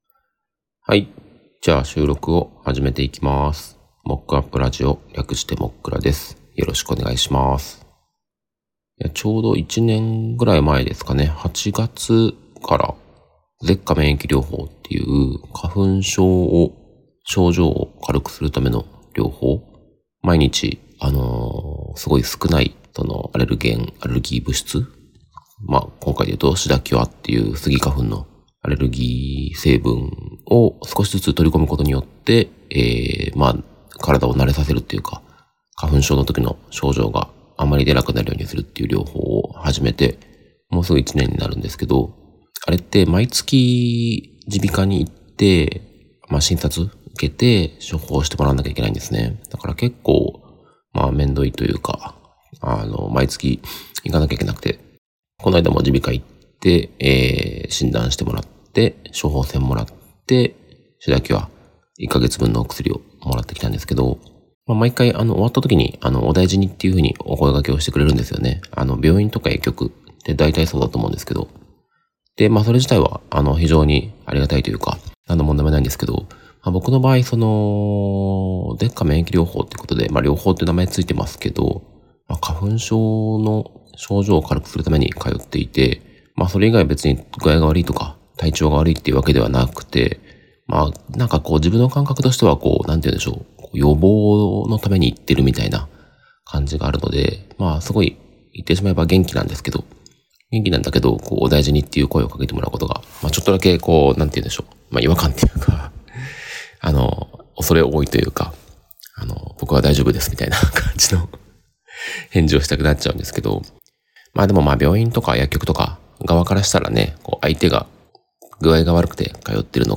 Audio Channels: 2 (stereo)